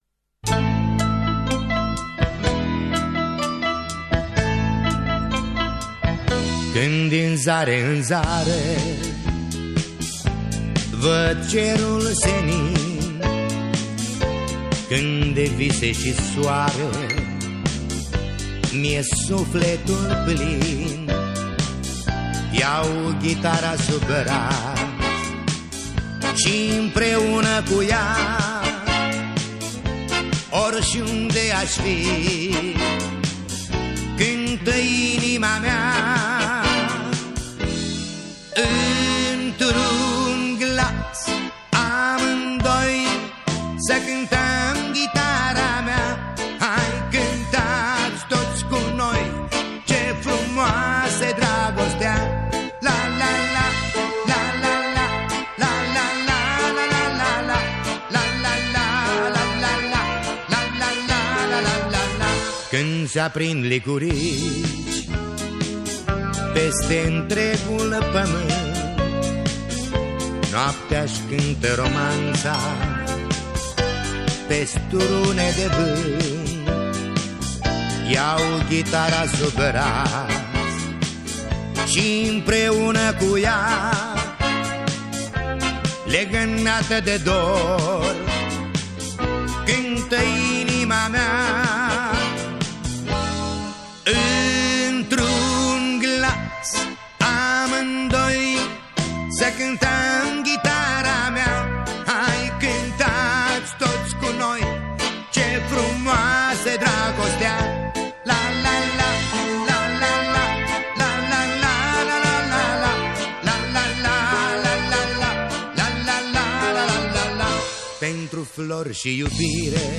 Interpretul de muzică ușoară